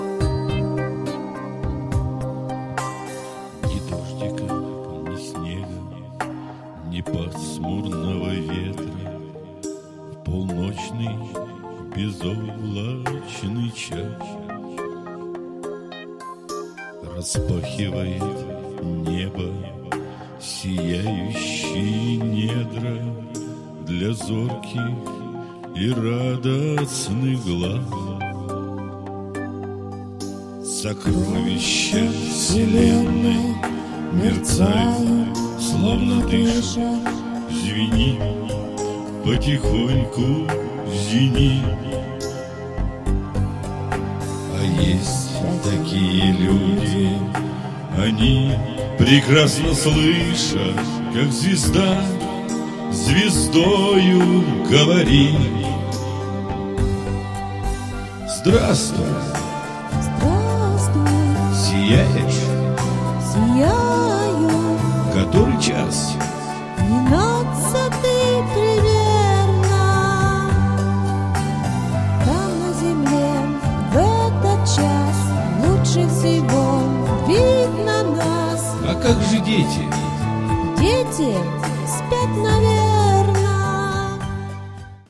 • Качество: 192, Stereo
мужской вокал
женский вокал
спокойные
колыбельные